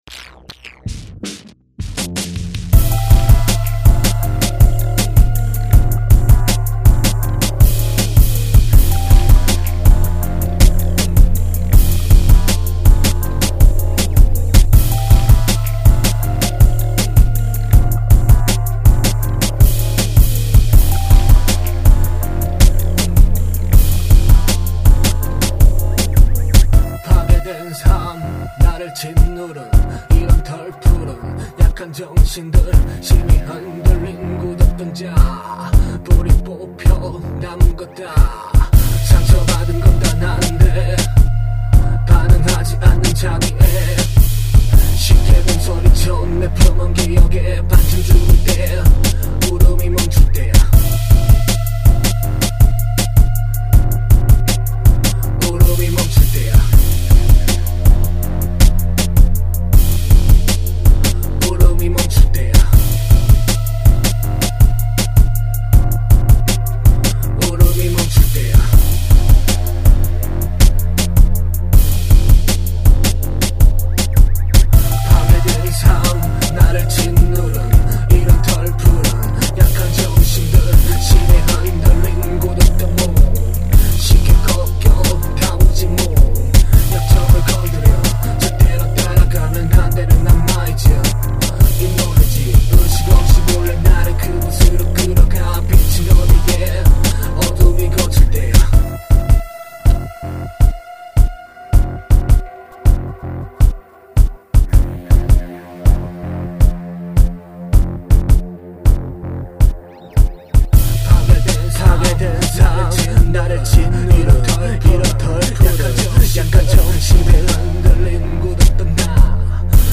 Triphop입니다.